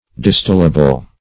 Search Result for " distillable" : The Collaborative International Dictionary of English v.0.48: Distillable \Dis*till"a*ble\ (d[i^]s*t[i^]l"[.a]*b'l), a. (Chem.)